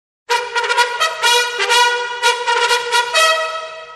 refrain_chamborant.mp3